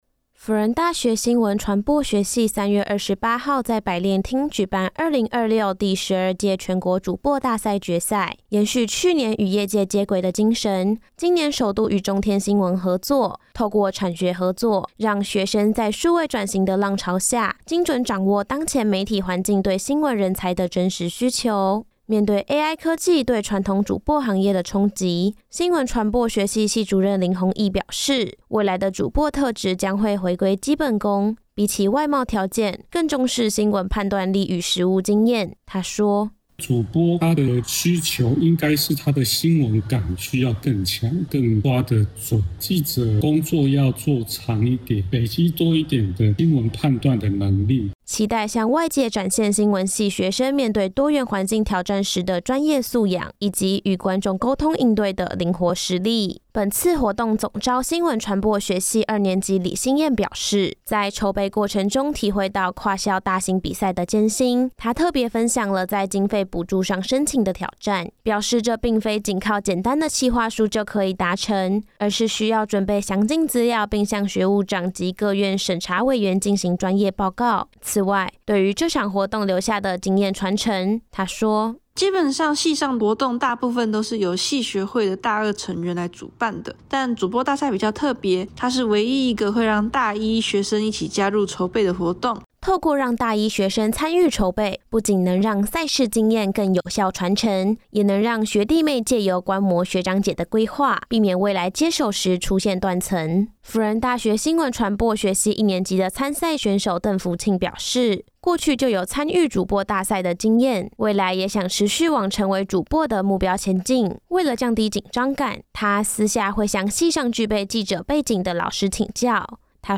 採訪報導